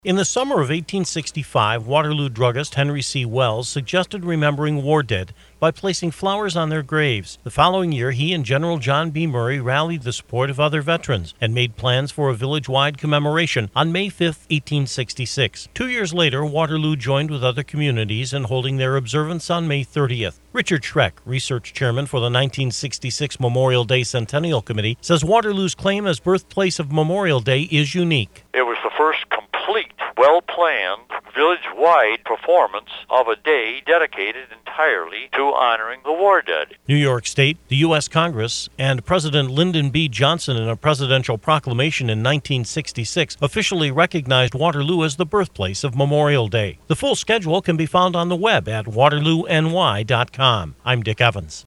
Newsstory2- fullread